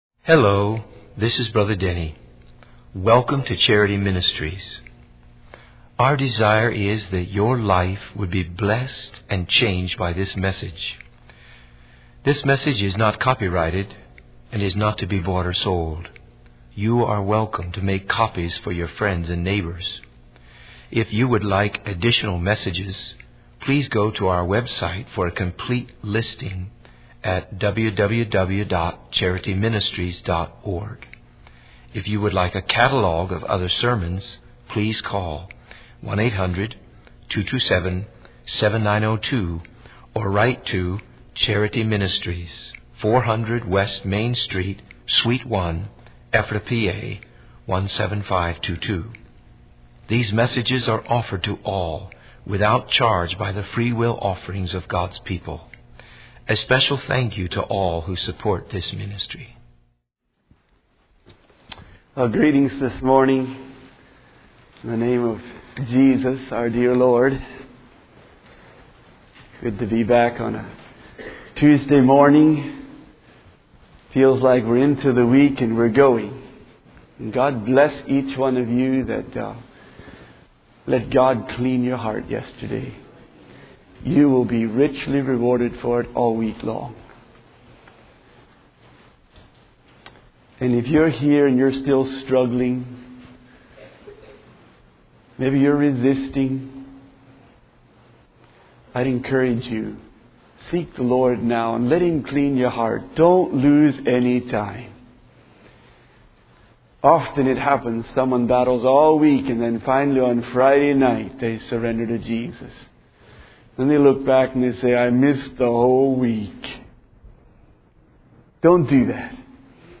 In this sermon, the preacher begins by reading Revelation 4:8, which describes four beasts with six wings each, full of eyes, continuously praising the Lord God Almighty.